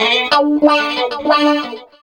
29 GUIT 5 -L.wav